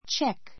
tʃék